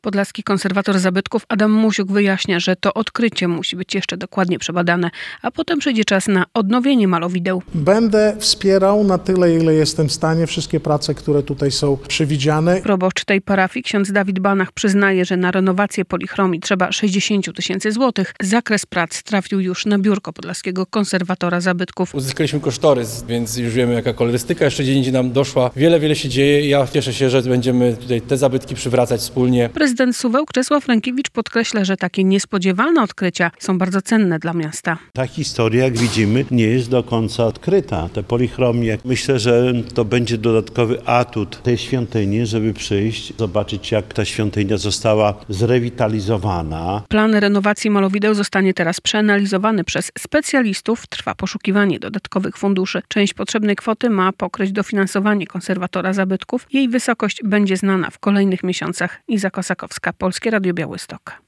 Podlaski Wojewódzki Konserwator Zabytków Adam Musiuk wyjaśnia, że to odkrycie musi być jeszcze dokładnie przebadane, a potem przyjdzie czas na ich odnowienie.
Prezydent Suwałk Czesław Renkiewicz podkreśla, że takie niespodziewane odkrycia są bardzo cenne dla miasta.